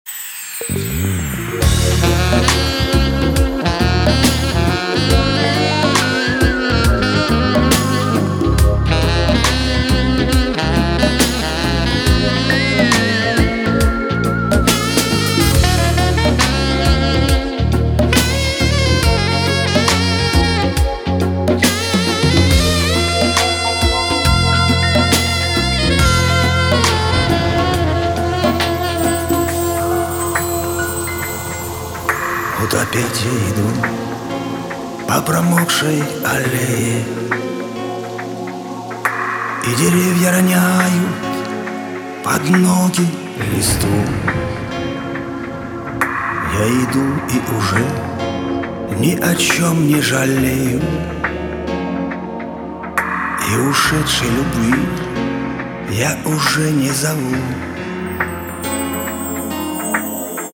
sax